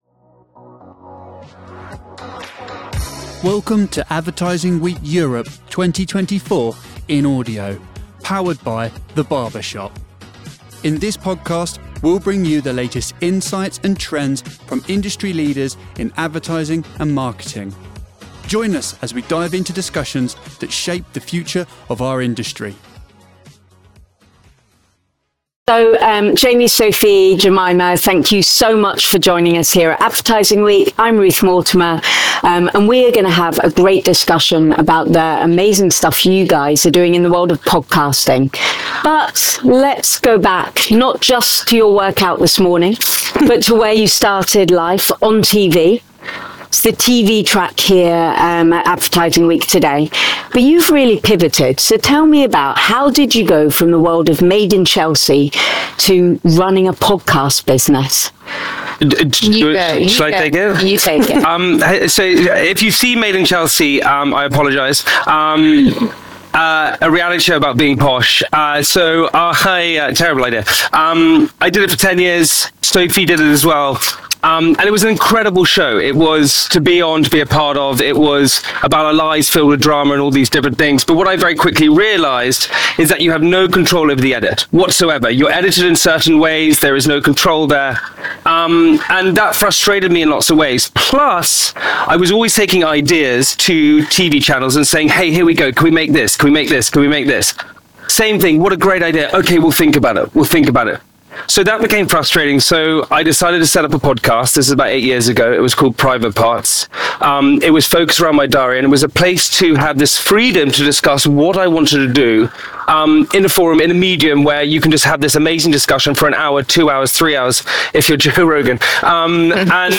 This engaging session explores the journey of building successful podcasts that resonate with Millennials, from content creation to audience engagement. Learn about the challenges and triumphs of podcasting, the importance of authenticity, and the strategies that have made JamPot Productions a leader in the field.